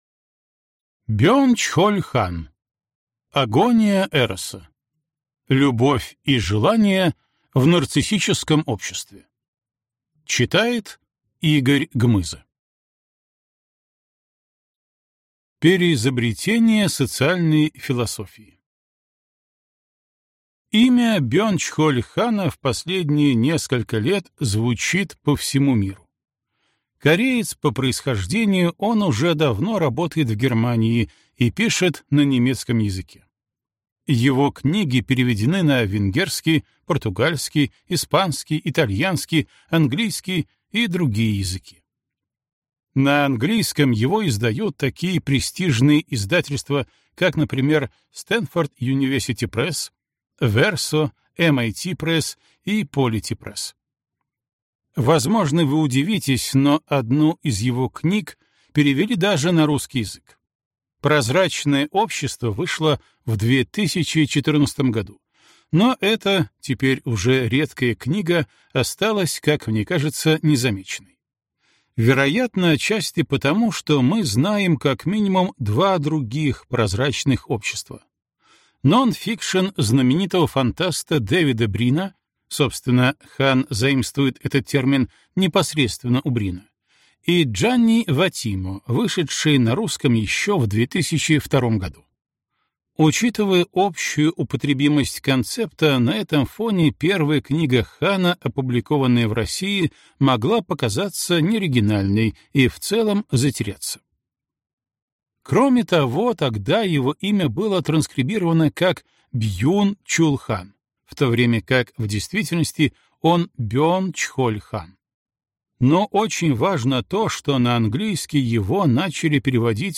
Аудиокнига Агония эроса. Любовь и желание в нарциссическом обществе | Библиотека аудиокниг